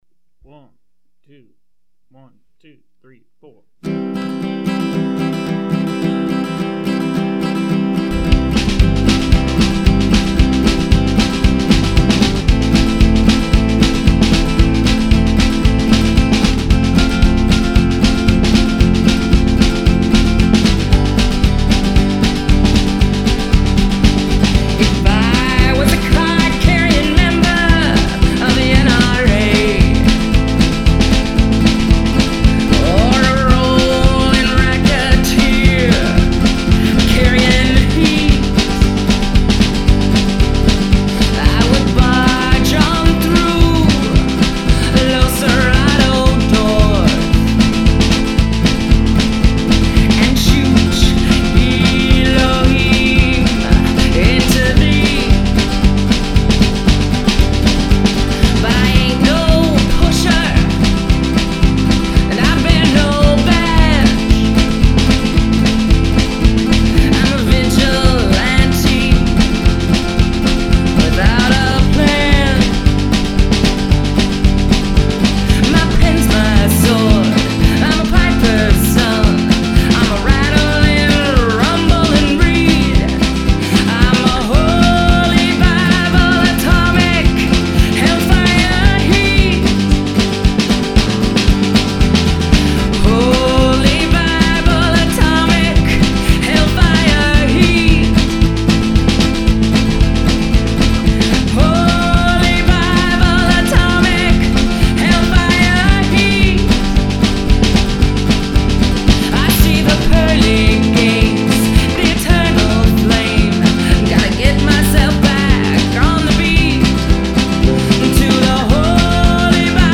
Guitar, Vocals, Bass, Harmonica
Drums